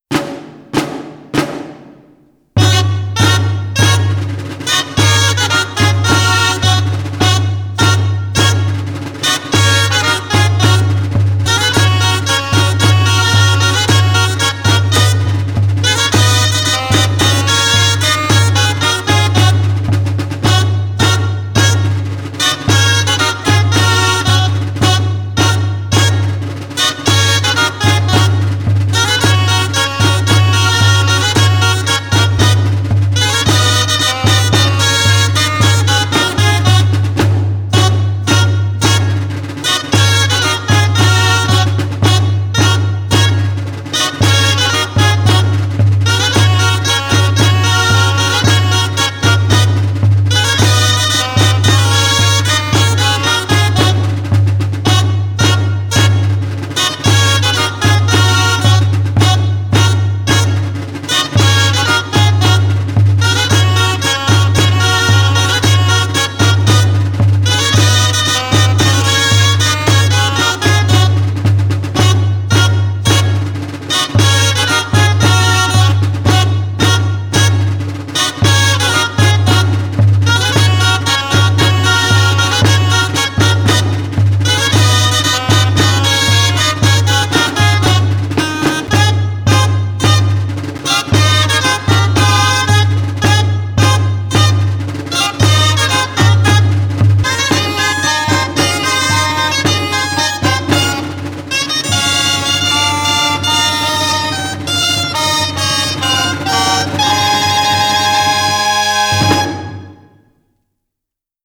17. BALL DEL PATATUF DE TARRAGONA Grallers Els Bordons